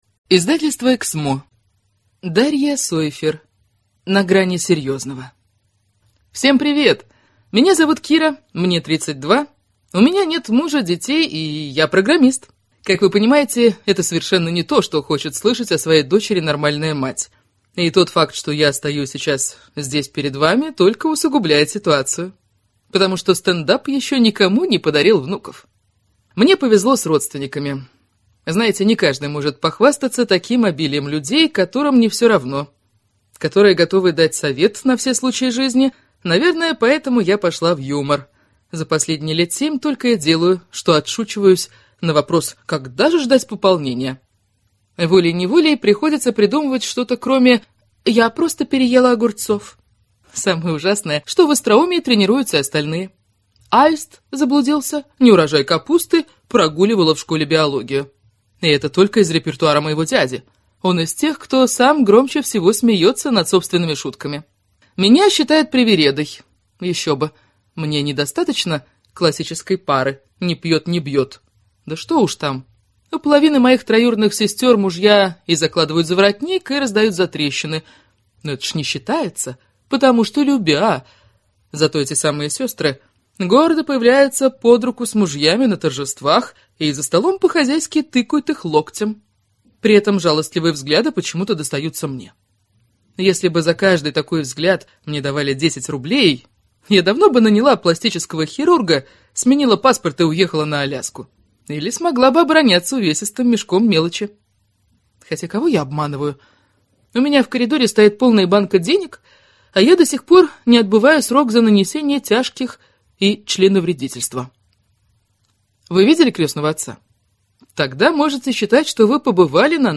Аудиокнига На грани серьёзного | Библиотека аудиокниг